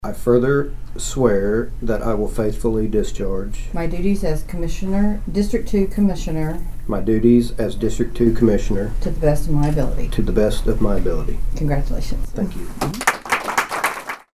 was sworn in early on Friday morning by Judge Linda Thomas.
Shivel Swearing In 12-6.mp3